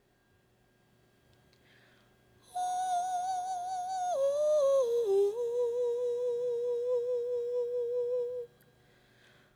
CD Audio Book (Spoken Word/Music)